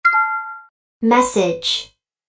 通知音と女性の声で「message」と話すSNSにピッタリな通知音。
「message」女性の声 着信音